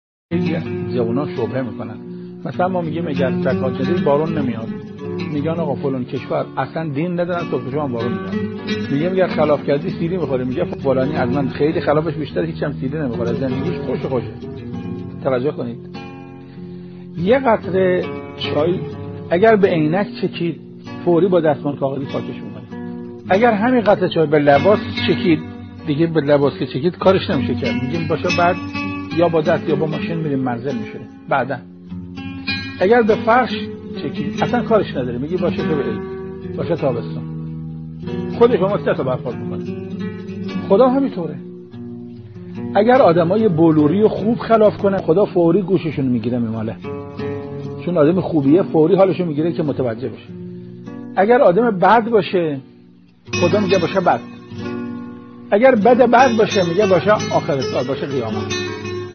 عقیق:استاد قرائتی در یکی از سخنرانی های خود به پرسش و پاسخی در رابطه با «شبهات نوجونان، پیرامون علت عدم عذاب ظالمان دنیا» پرادخت که تقدیم شما فرهیختگان می‌شود.